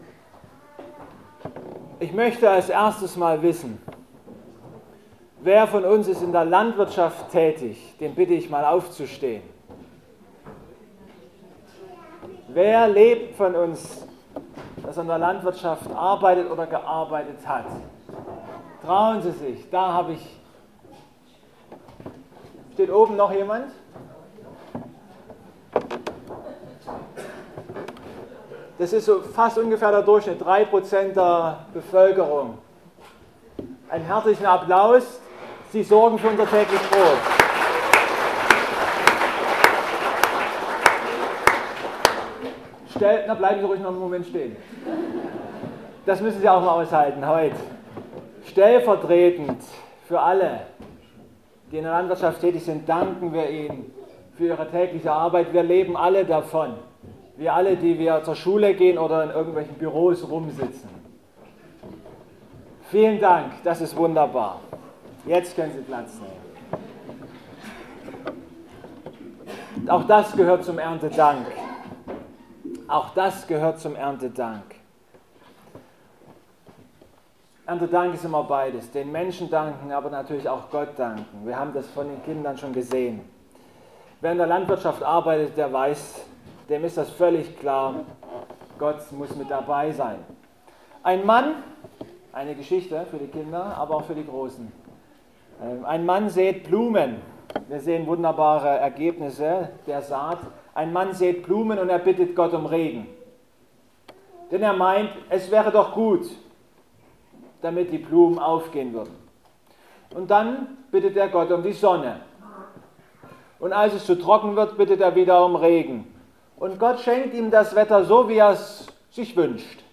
Matthäus 6,25-34 Gottesdienstart: Familiengottesdienst Ein Mann baut einen Turm.